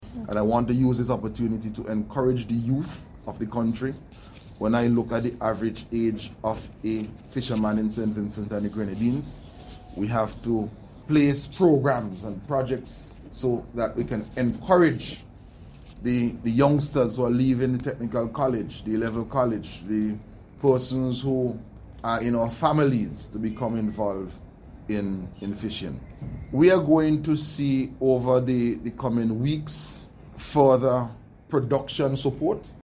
Speaking at a recent ceremony, Minister of Agriculture, Saboto Caesar, acknowledged the aging population of fisher folk, underlining the importance of engaging younger generations to ensure the industry’s sustainability.